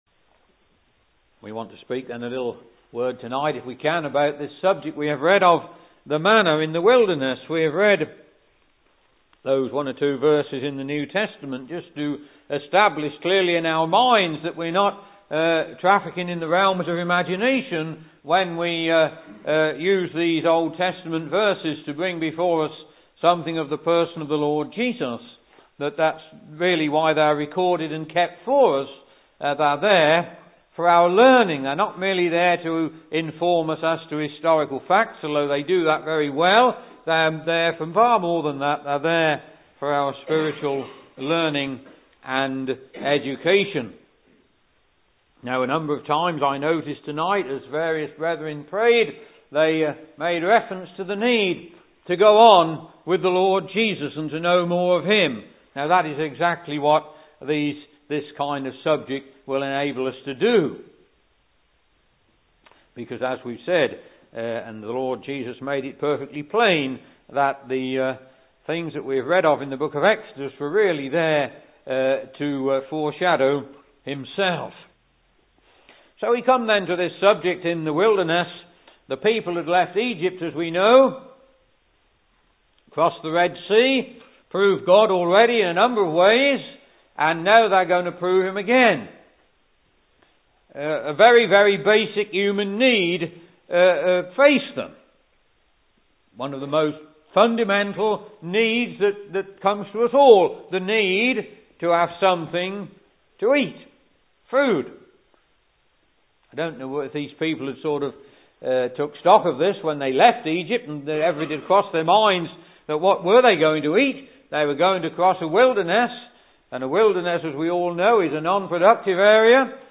He draws Christ-exalting and practical teaching and lessons from this beautiful Old Testament type (Message preached 12th Jan 2012)